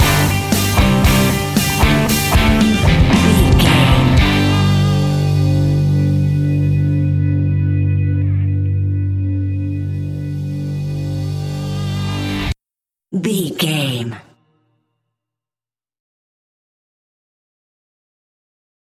One Movement Stinger.
Epic / Action
Ionian/Major
hard rock
Rock Bass
heavy drums
distorted guitars
hammond organ